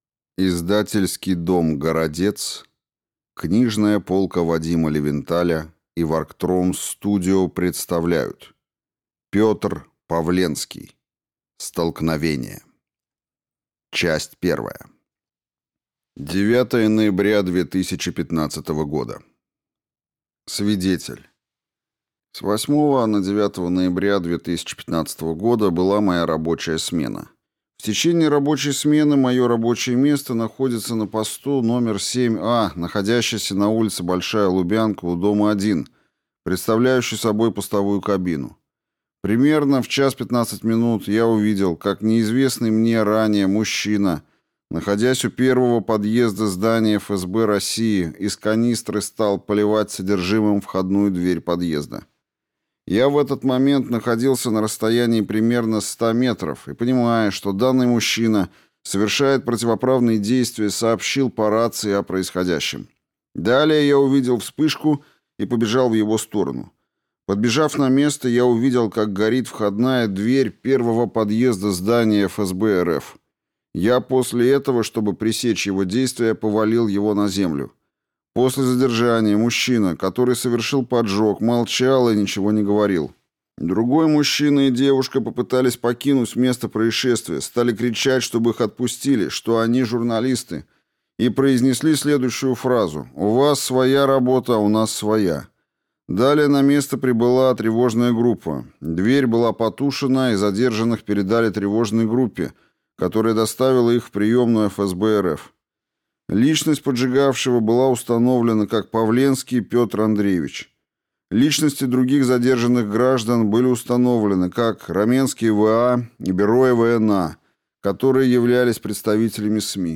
Аудиокнига Столкновение | Библиотека аудиокниг